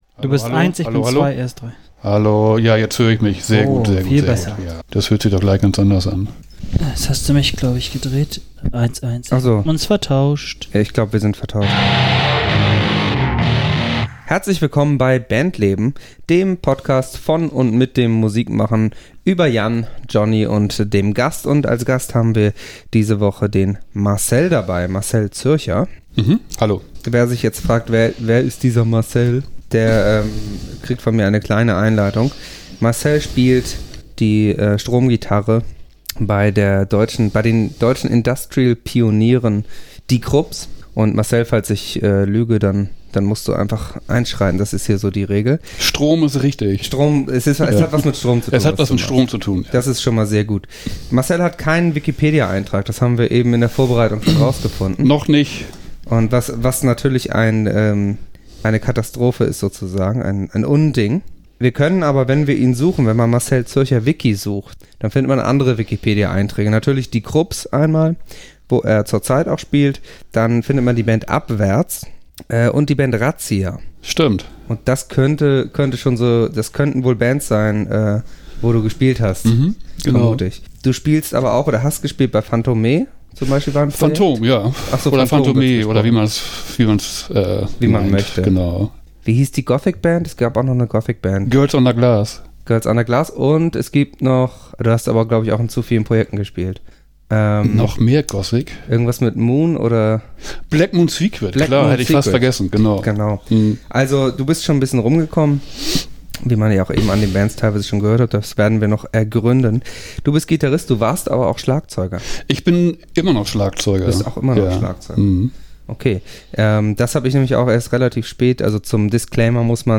in seiner guten Stube besucht